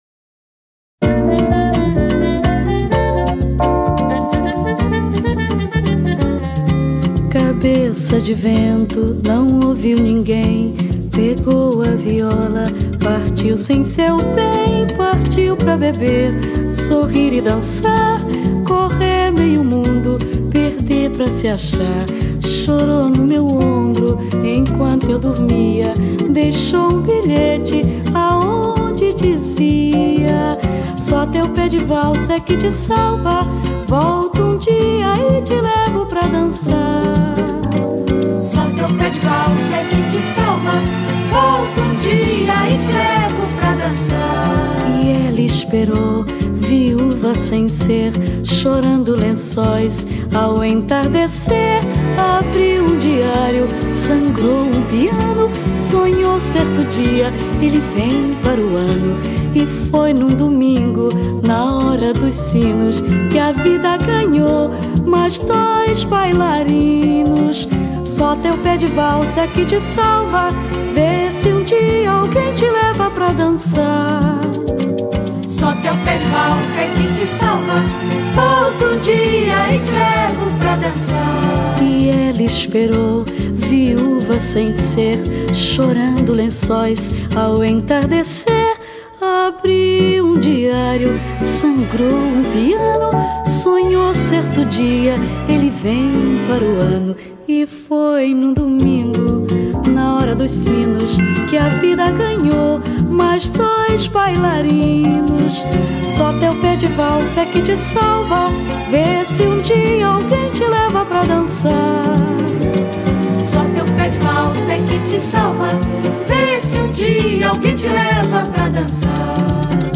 Voz: Maria Creuza